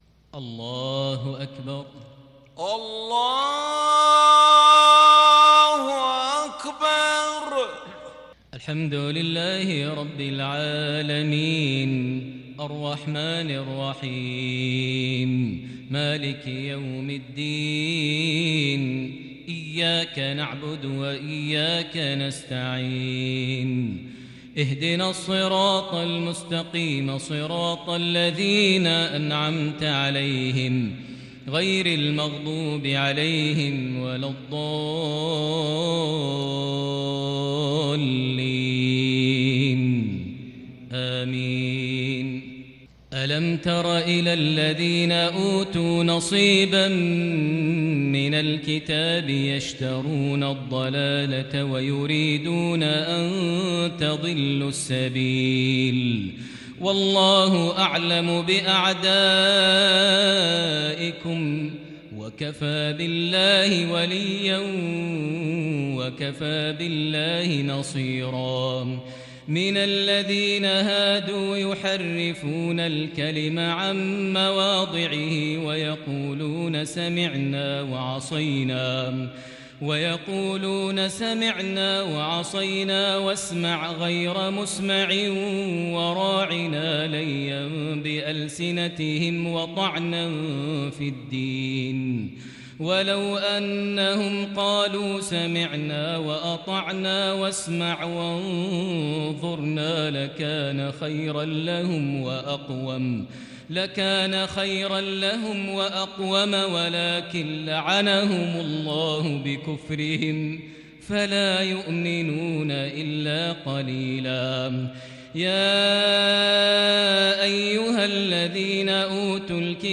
صلاة العشاء للشيخ ماهر المعيقلي 21 صفر 1442 هـ
تِلَاوَات الْحَرَمَيْن .